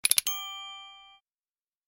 • Качество: Хорошее
• Категория: Рингтон на смс